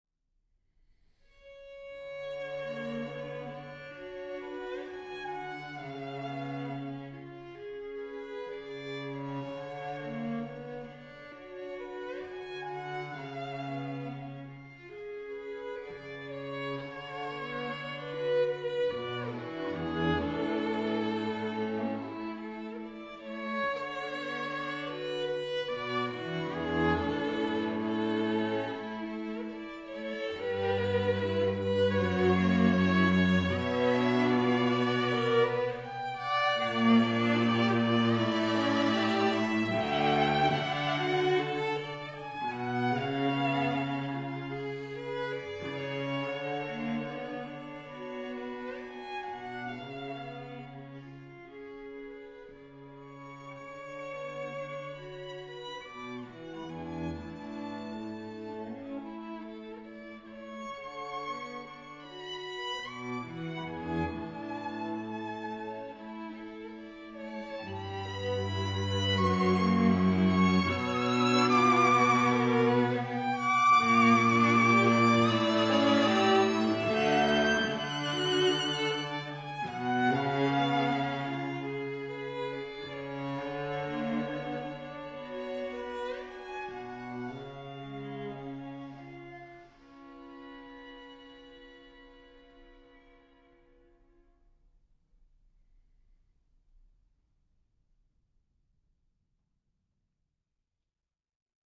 Aldbury Parish Church